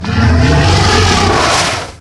Monster5.ogg